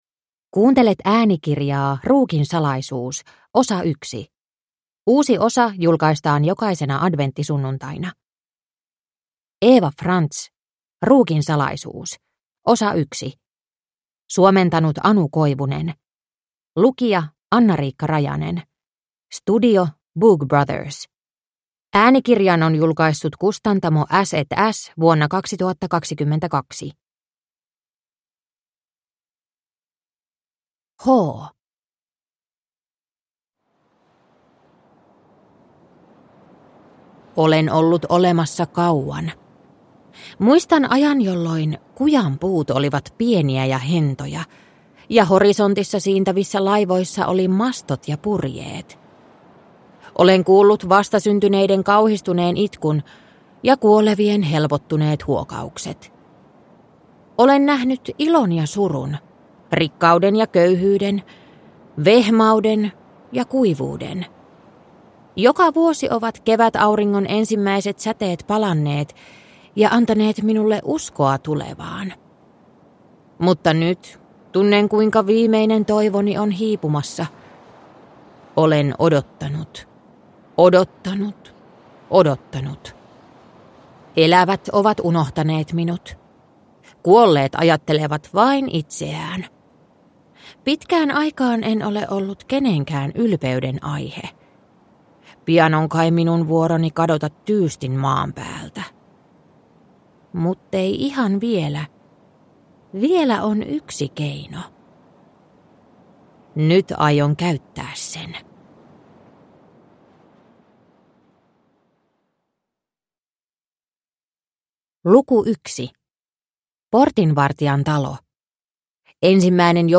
Entä mitä tapahtui sen omistaneelle von Hiemsin perheelle?Kihelmöivän jännittävä äänikirja on jaettu neljään osaan, jotka julkaistaan viikon välein jolukuussa.